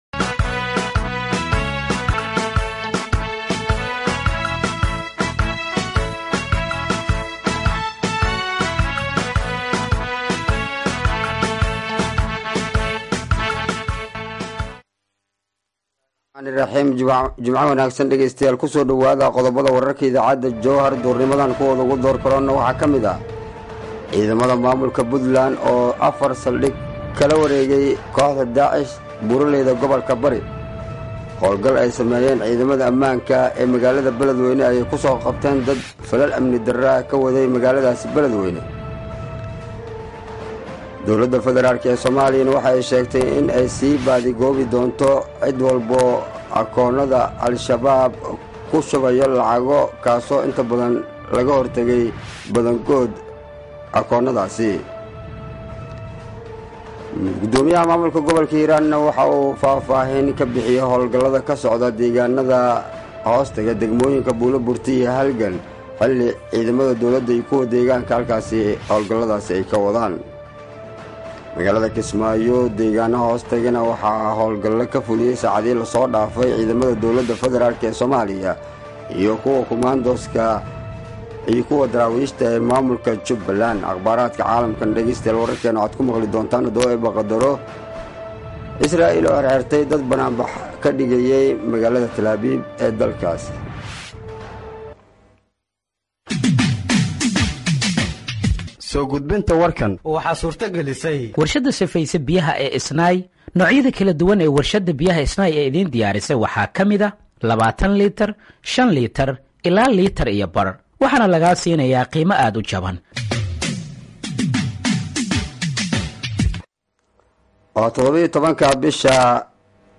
Dhageeyso Warka Duhurnimo ee Radiojowhar 17/01/2025